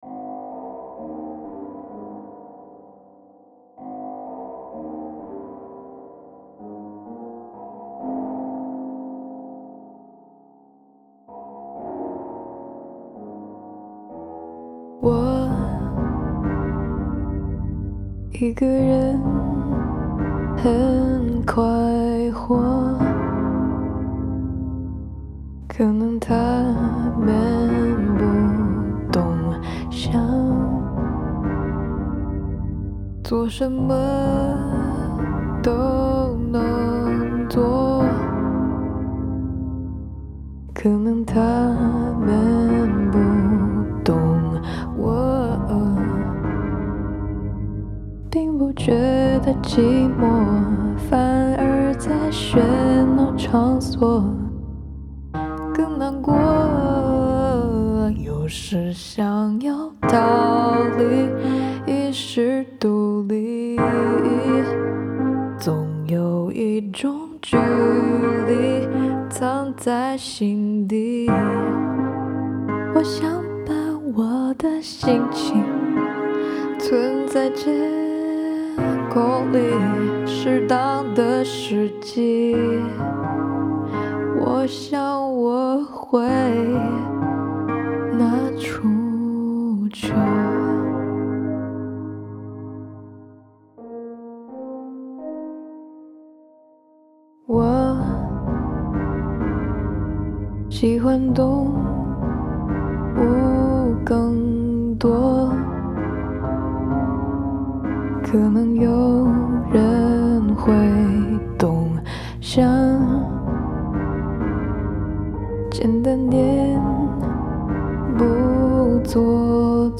Genre: C-Pop
Version: Demo